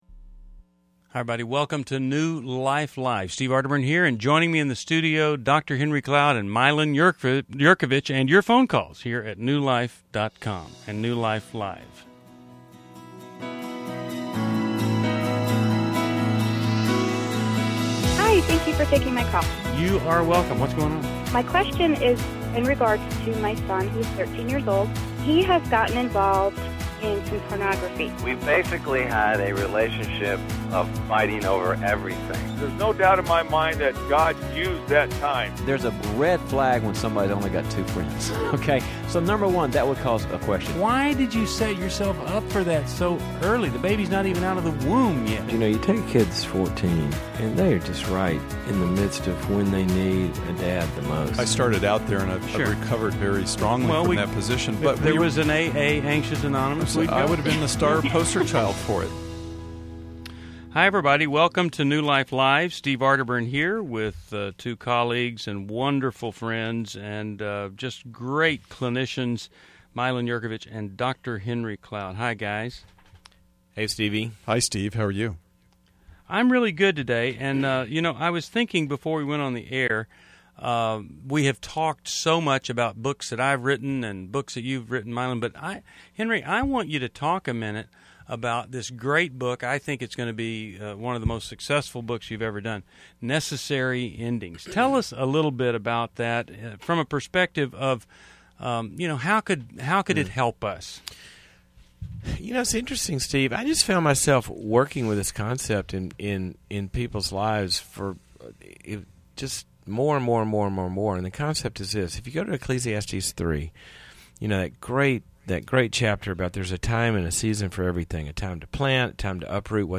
Explore co-dependency, dating dynamics, and parenting insights as our hosts tackle real caller questions on New Life Live: June 27, 2011.